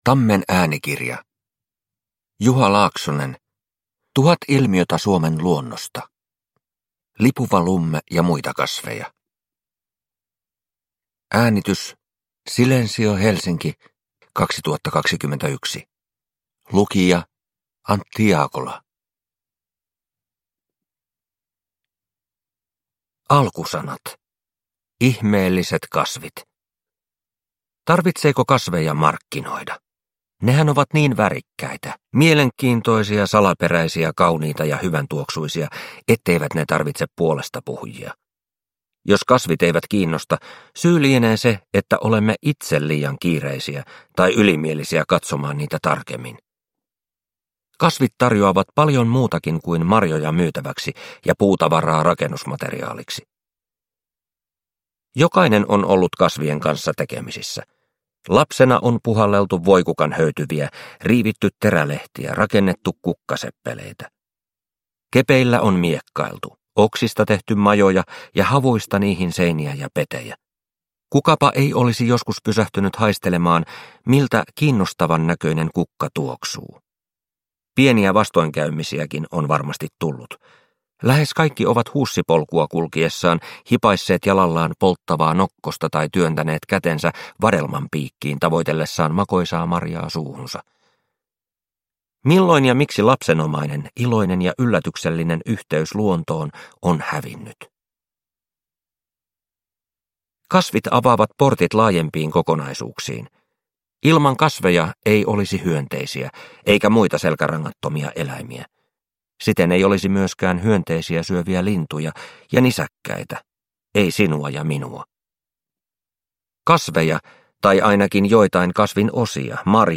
Lipuva lumme ja muita kasveja – Ljudbok – Laddas ner